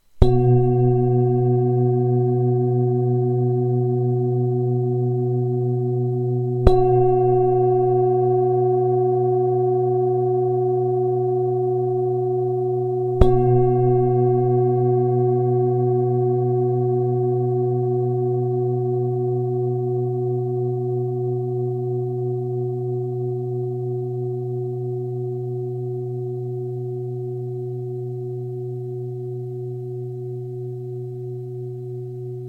Zdobená tibetská mísa B2 25cm
Nahrávka mísy úderovou paličkou:
Jde o ručně tepanou tibetskou zpívající mísu dovezenou z Nepálu.